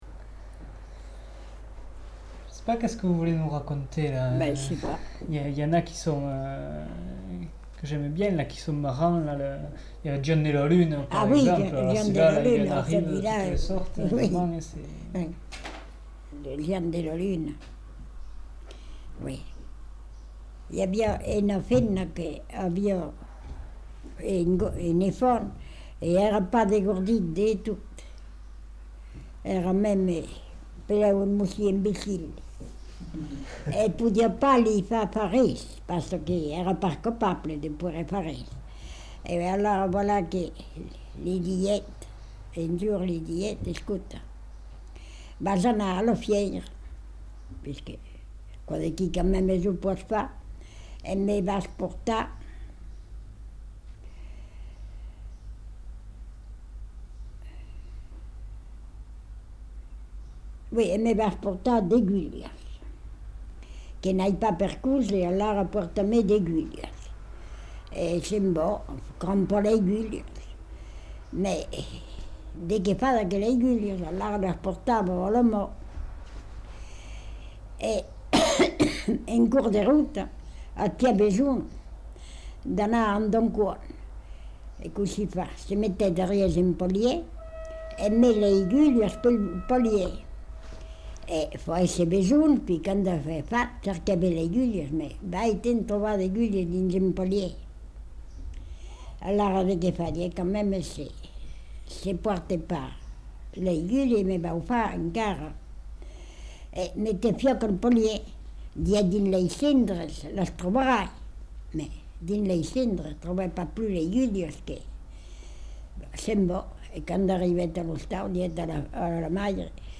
Genre : conte-légende-récit
Type de voix : voix de femme Production du son : parlé